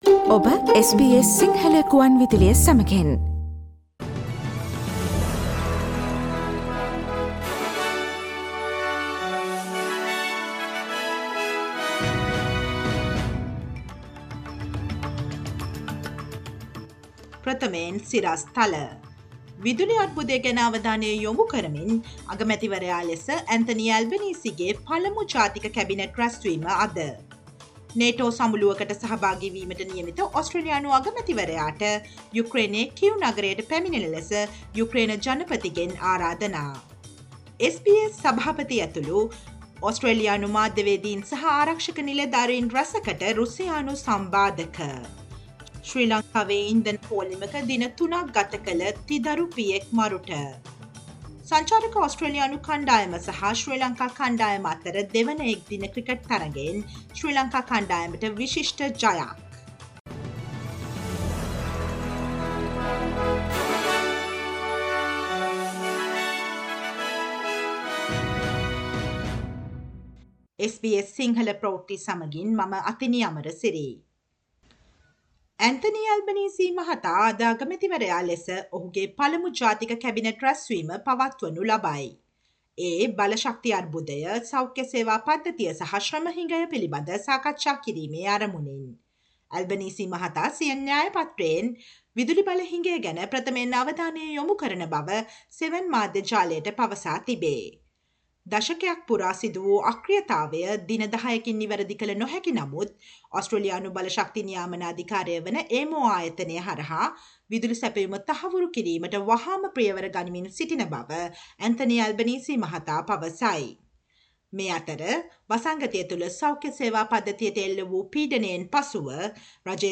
ඔස්ට්‍රේලියාවේ සහ ශ්‍රී ලංකාවේ නවතම පුවත්, විදෙස් පුවත් සහ ක්‍රීඩා පුවත් රැගත් SBS සිංහල සේවයේ 2022 ජූනි 17 වන දා සිකුරාදා වැඩසටහනේ ප්‍රවෘත්ති ප්‍රකාශයට සවන්දෙන්න ඉහත ඡායාරූපය මත ඇති speaker සලකුණ මත click කරන්න.